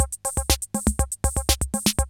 CR-68 LOOPS4 3.wav